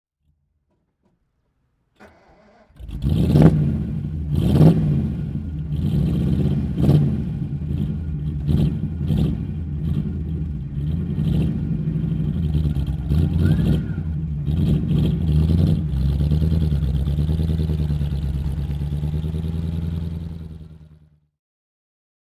Ford Comète (1953) - Starten und Leerlauf
Ford_Comete_1953.mp3